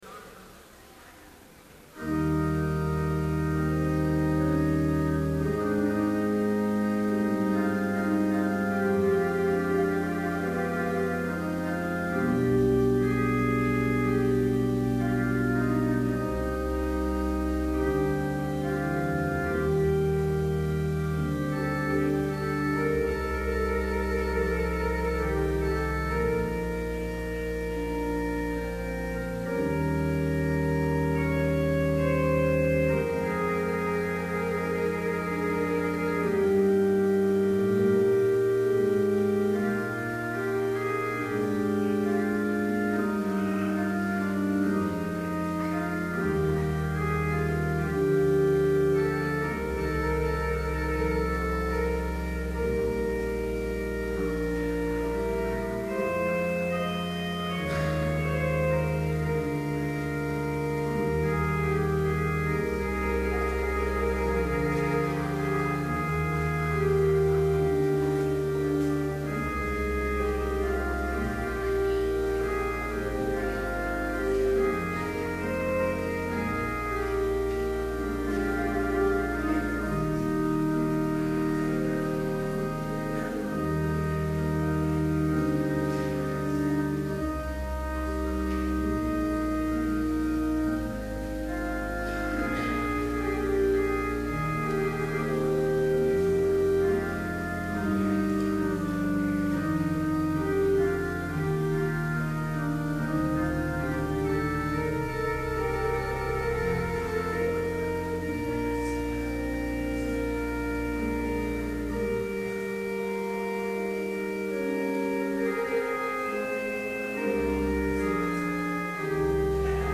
Chapel in Trinity Chapel, Bethany Lutheran College, on March 29, 2012, (audio available) with None Specified preaching.
Complete service audio for Chapel - March 29, 2012